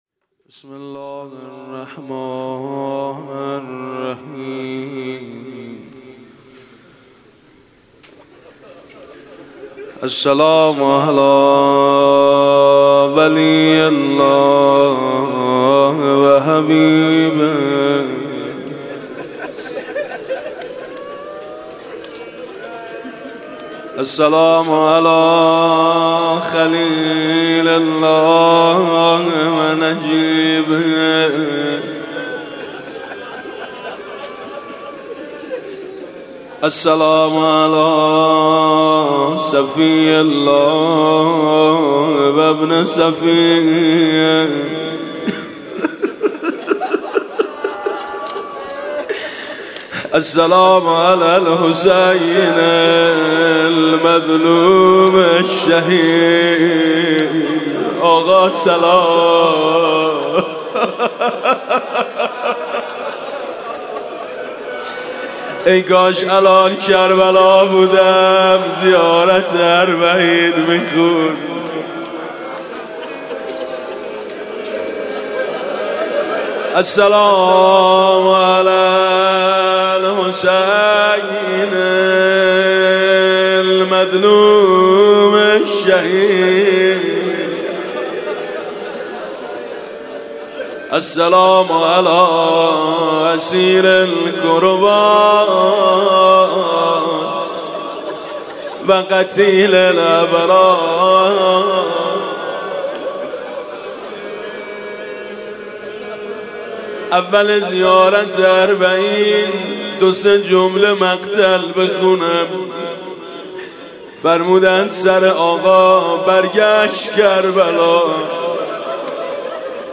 فایل صوتی زیارت اربعین با صدای میثم مطیعی